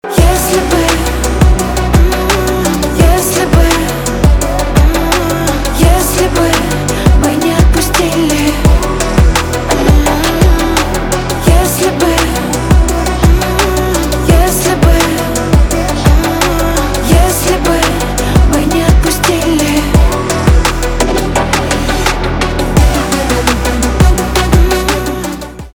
поп
грустные
чувственные
битовые , басы